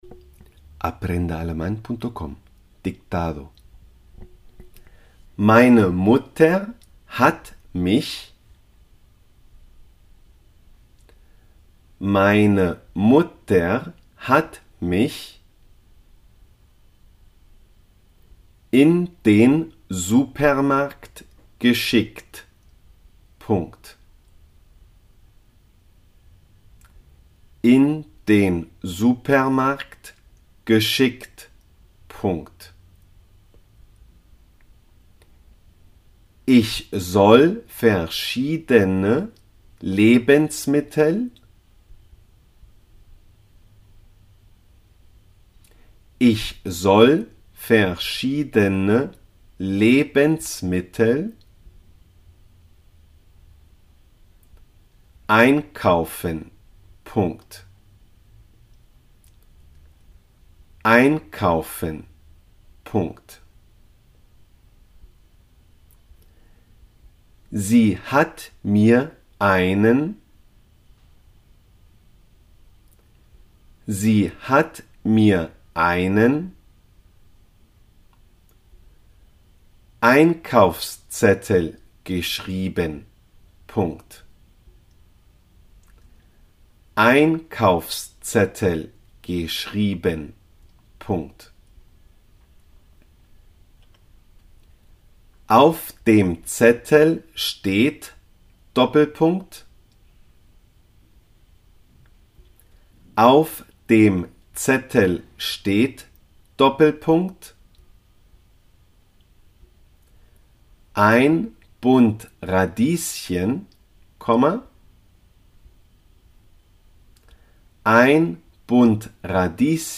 der Supermarkt – el supermeracdo .dictado
el-supermeracdo-der-Supermarkt-dictado-en-aleman-Aprende-1.mp3